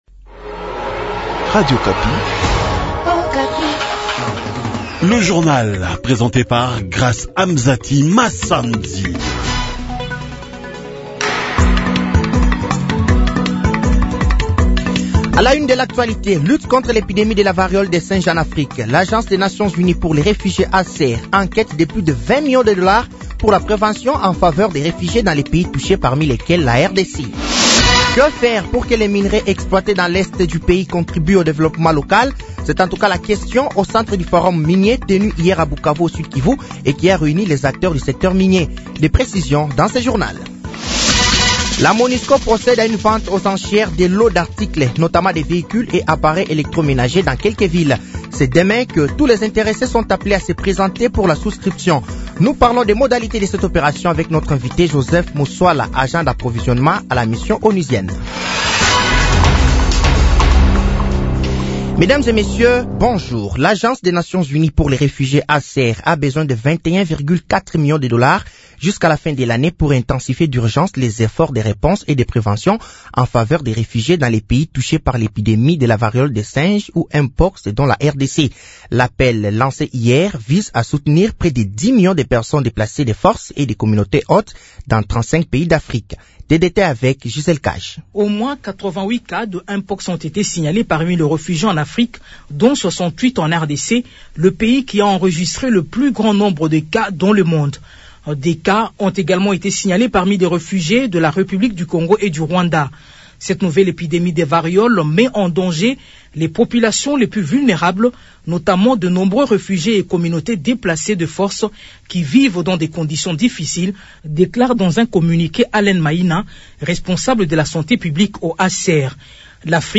Journal français de 6h de ce jeudi 12 septembre 2024